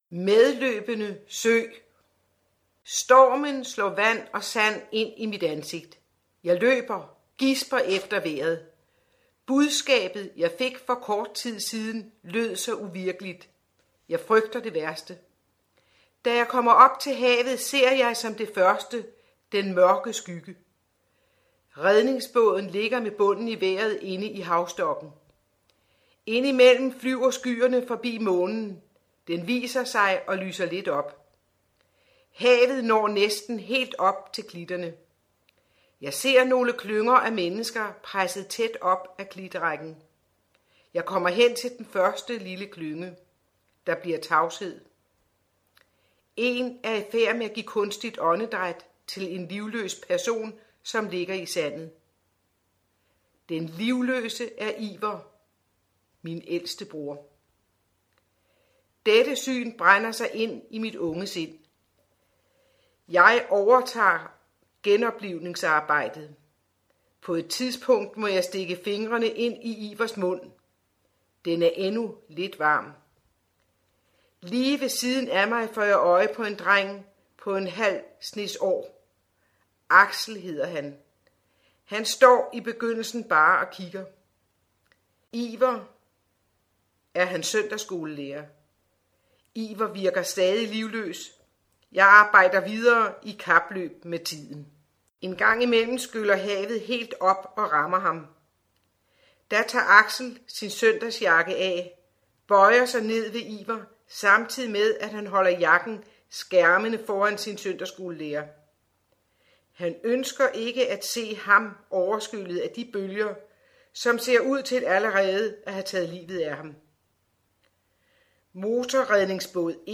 Lydbog